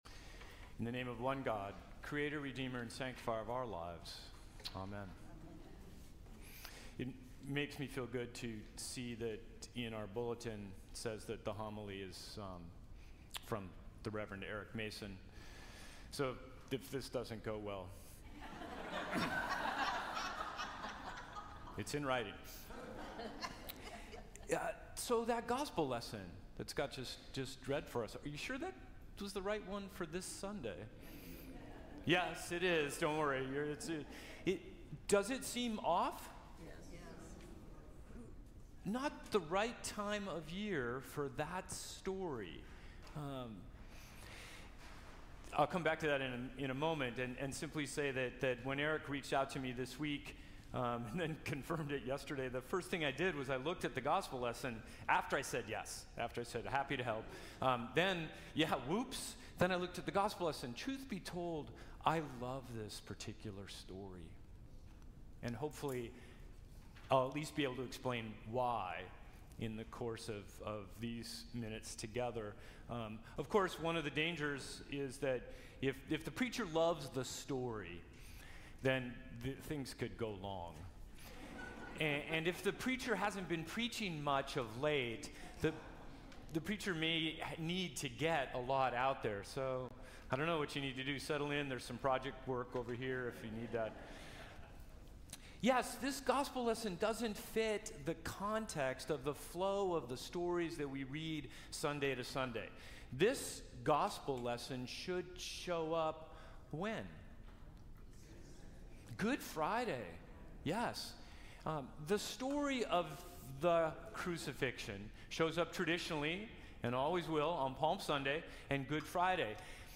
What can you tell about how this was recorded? Last Sunday after Pentecost